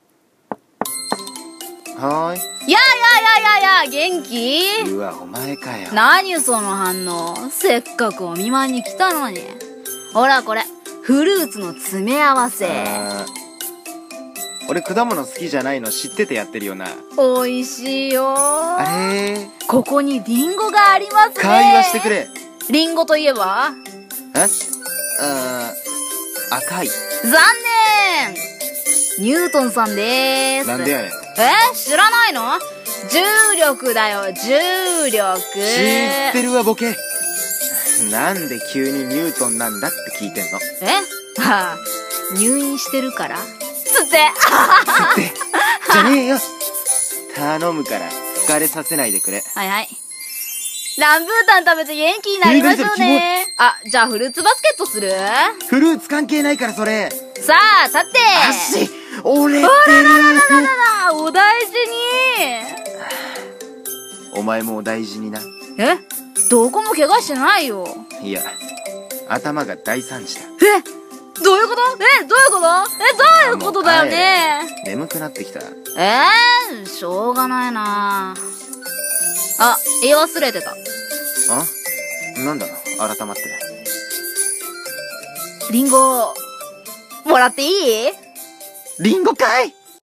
【声劇】お見舞い